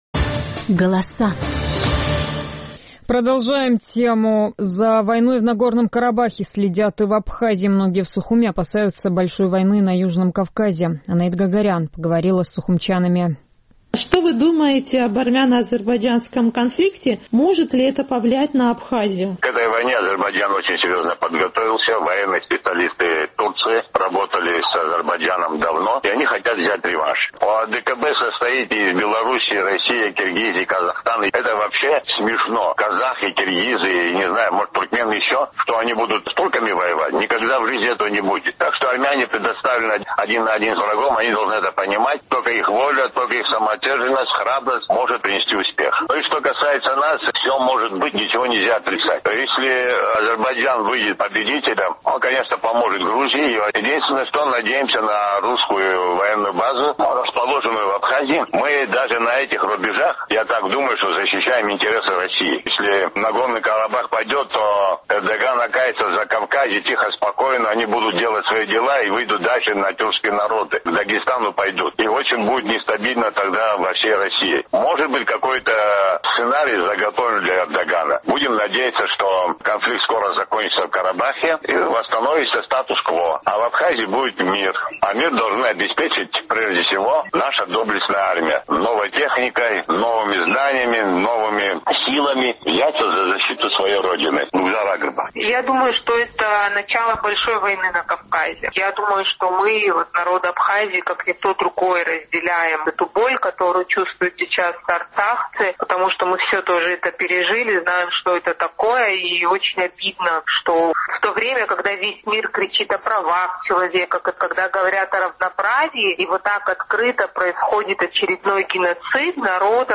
В Абхазии многие опасаются большой войны на Южном Кавказе. Наш традиционный сухумский опрос.